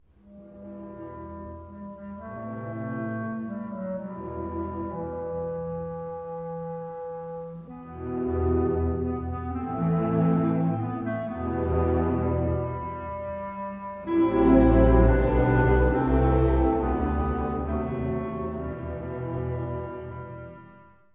(序奏) 古い音源なので聴きづらいかもしれません！
Clの暗い旋律…この交響曲全体を支配する旋律です。